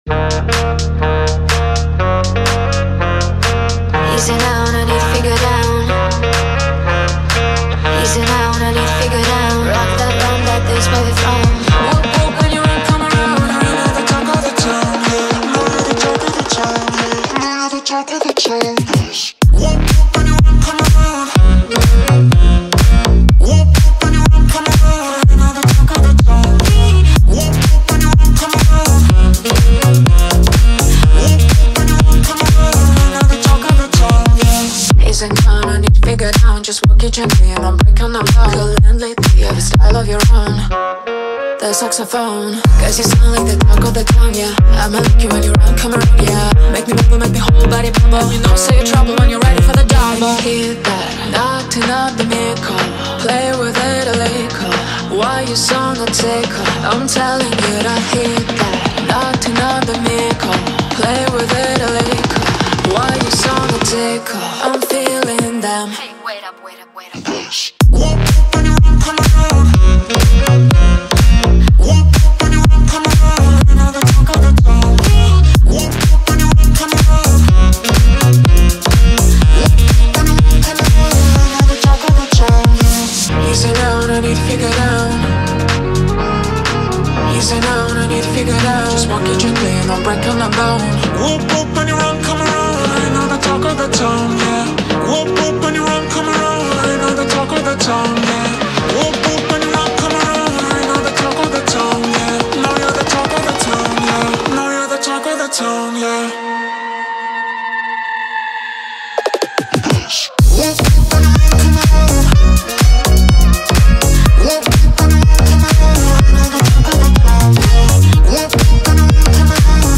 Интенсивные биты и запоминающаяся мелодия